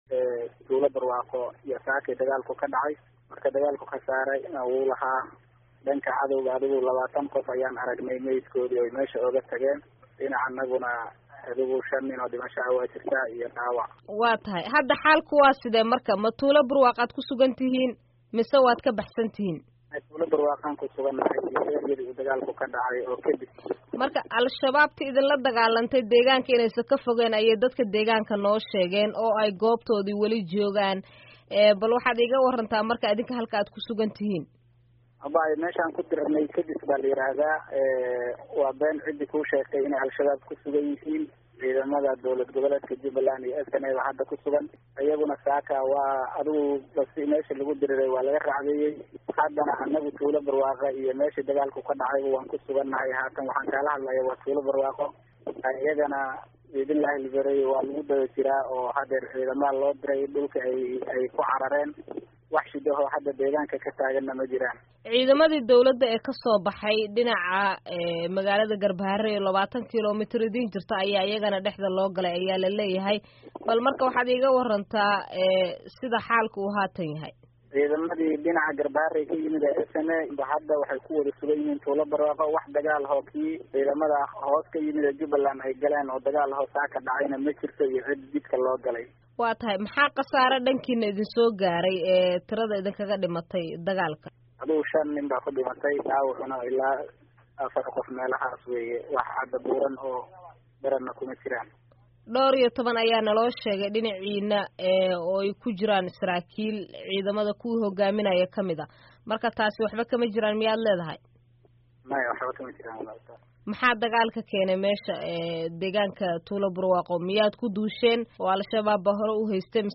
Wareysi: Dagaalka Gedo